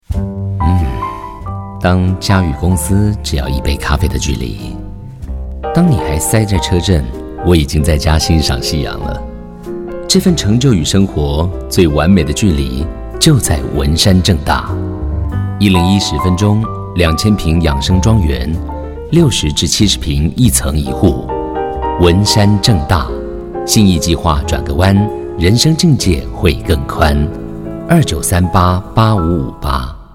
國語配音 男性配音員
廣告配音員